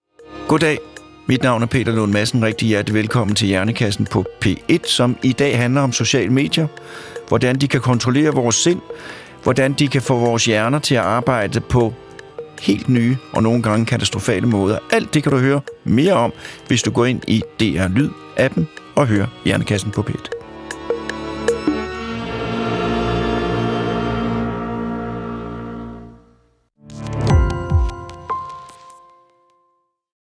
Peter Lund Madsen får hver uge besøg af eksperter i studiet. Alle emner kan blive belyst - lige fra menneskekroppens tarmflora til ekspeditioner på fremmede planeter.